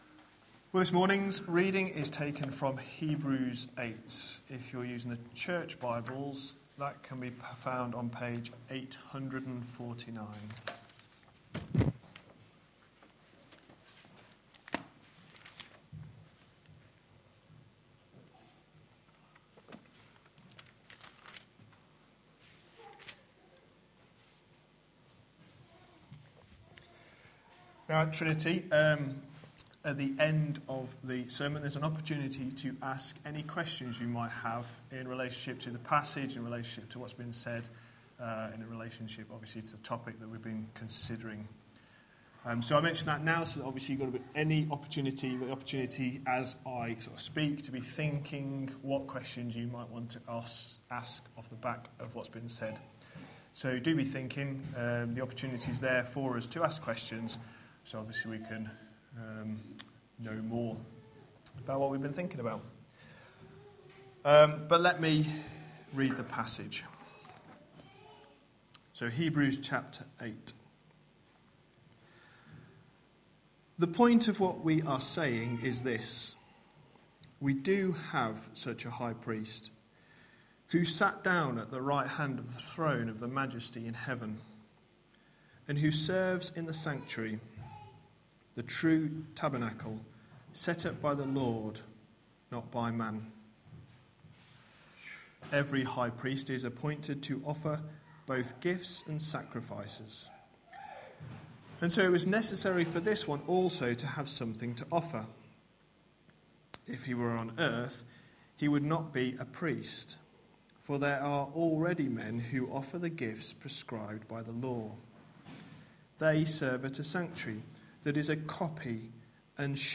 A sermon preached on 1st February, 2015, as part of our Hebrews series.